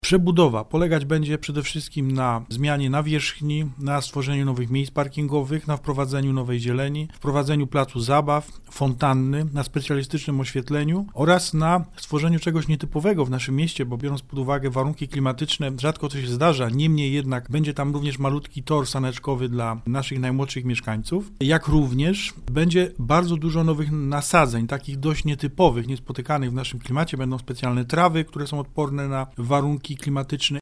– Zabiegaliśmy rok o pieniądze, ale mamy już 4 miliony złotych i możemy zaczynać remont – mówi Tomasz Ciszewicz, burmistrz Słubic.